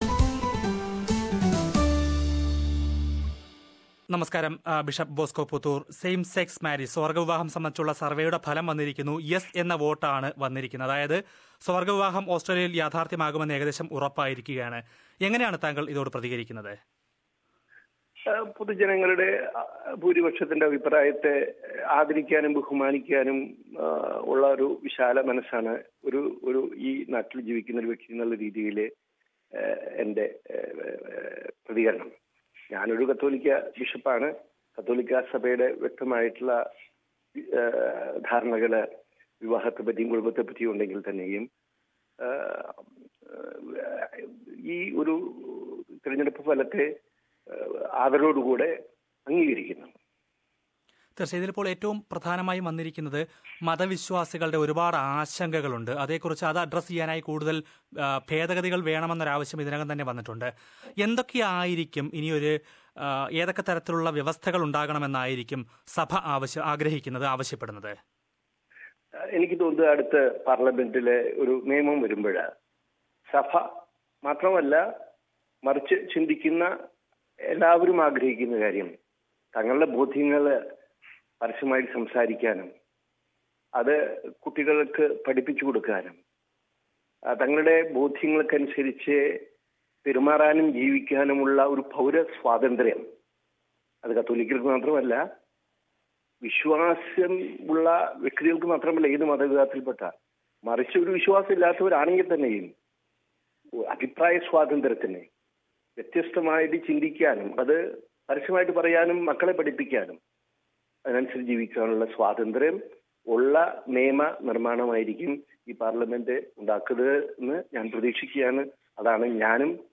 കത്തോലിക്കാ സഭയിലെ ആരെങ്കിലും സ്വവര്‍ഗ്ഗ പങ്കാളിയെ വിവാഹം കഴിക്കുയാണെങ്കില്‍ പിന്നെ അവര്‍ക്ക് സഭാംഗമായി തുടരാന്‍ കഴിയില്ലെന്നും ബിഷപ്പ് വ്യക്തമാക്കി. അഭിമുഖം കേള്‍ക്കാം, മുകളിലെ പ്ലേയറില്‍ നിന്ന്...